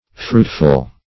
Fruitful \Fruit"ful\, a.